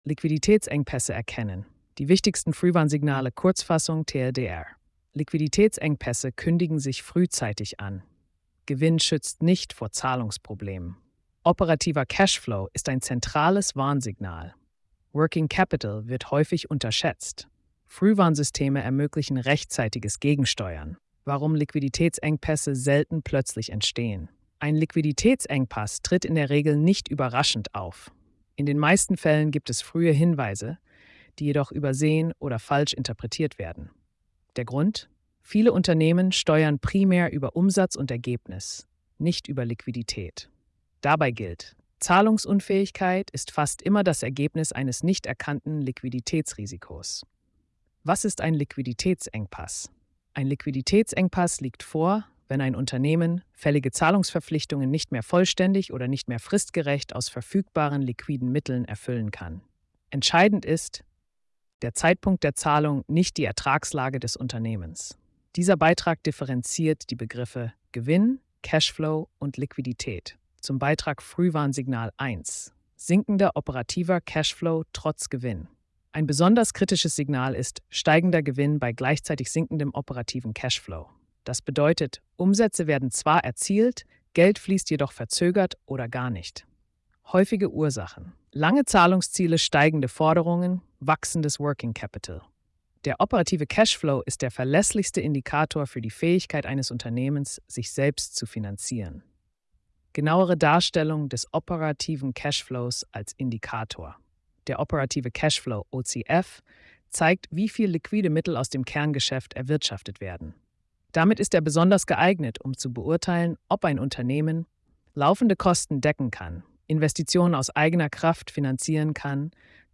Symbolbild Sprachausgabe